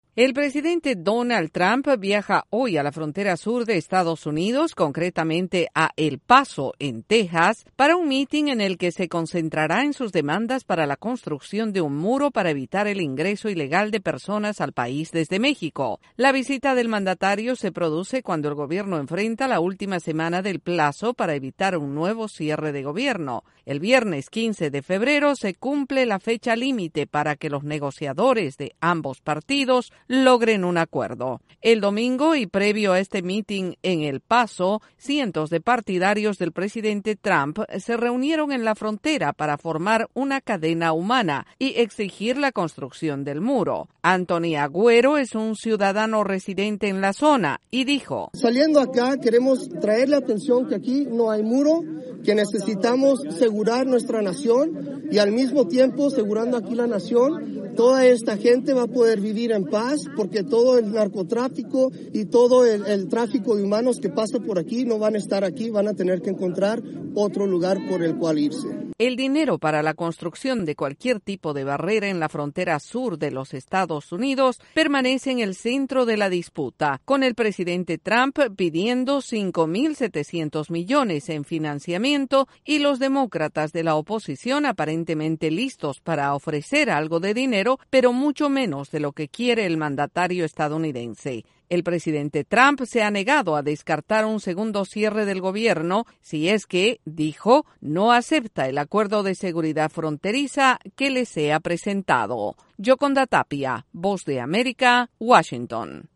En una semana decisiva para evitar otro probable cierre parcial del gobierno, el presidente Donald Trump realizará un mitin en Texas para insistir en la construcción del muro fronterizo. Desde la Voz de América en Washington DC informa